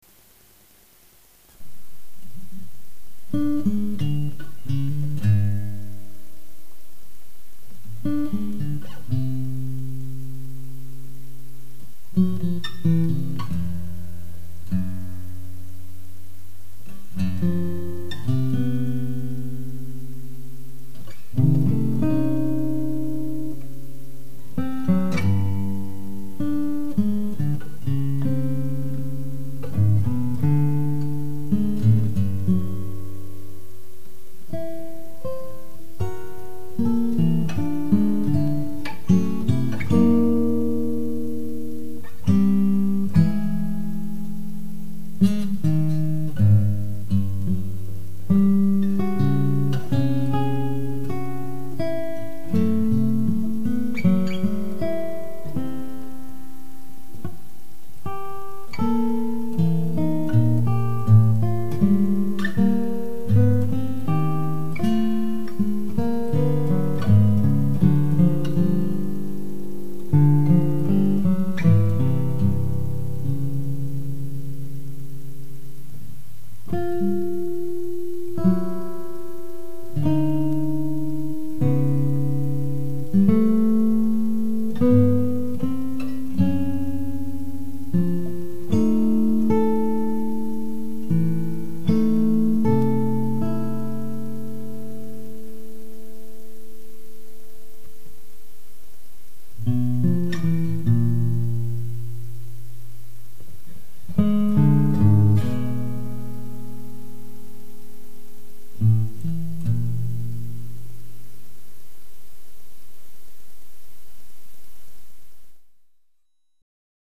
Duet for Two Guitars